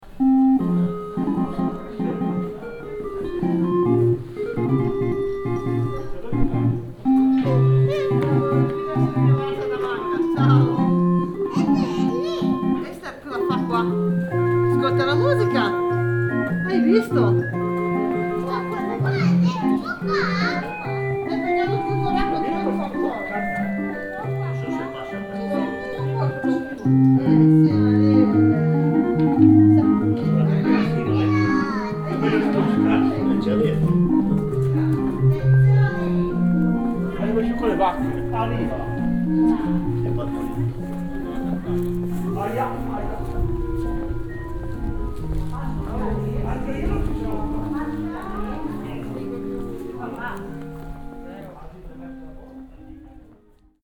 Organetto di Barberia - Città Metropolitana di Torino...
Rumore
L'organetto di Barberia è uno strumento musicale meccanico realizzato con canne, un mantice e dei cilindri, che suona ruotando una manovella previa l'inserimento di un disco di cartone traforato Novalesa
Microfoni binaurali stereo SOUNDMAN OKM II-K / Registratore ZOOM H4n
Organetto-Barberia.mp3